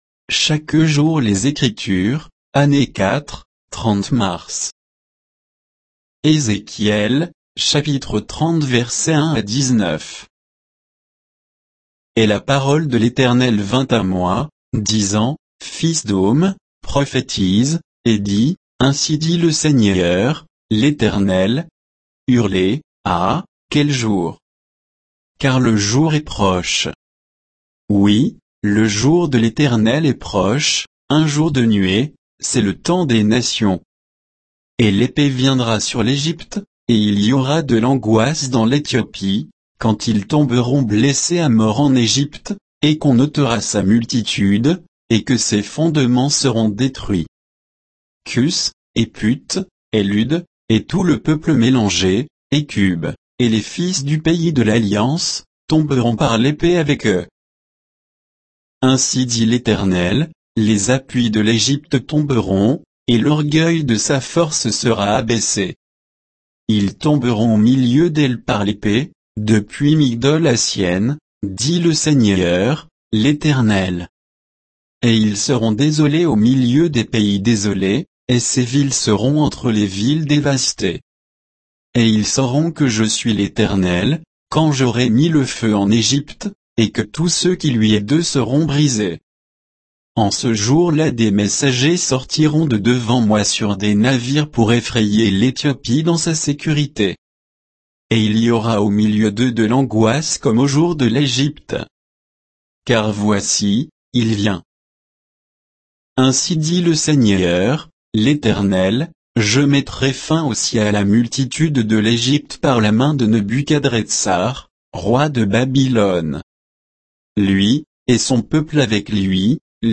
Méditation quoditienne de Chaque jour les Écritures sur Ézéchiel 30, 1 à 19